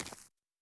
奔跑-左.wav